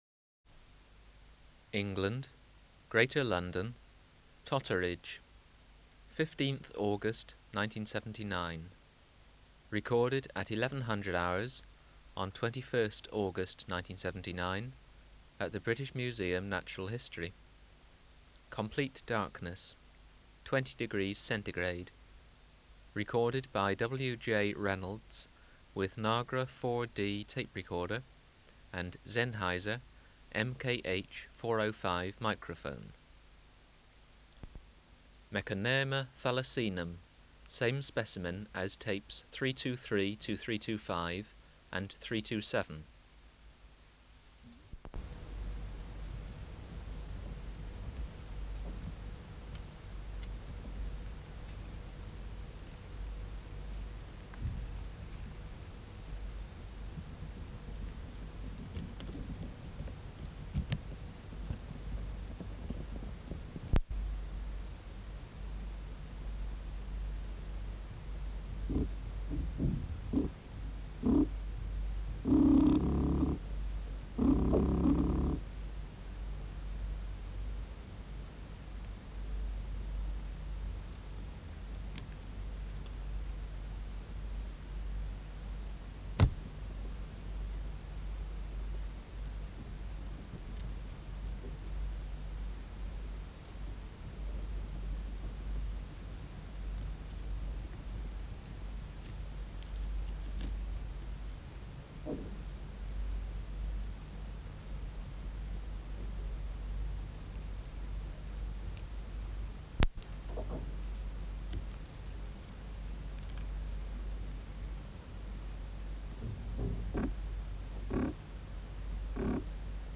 Recording Location: BMNH Acoustic Laboratory
Reference Signal: 1 kHz for 10 s
Extraneous Noise: Bangs
Cut A - Leafy oak twigs in netting - drumming on twigs?
Microphone & Power Supply: Sennheiser MKH 405 Distance from Subject (cm): 15
Recorder: Kudelski Nagra IV D (-17dB at 50Hz)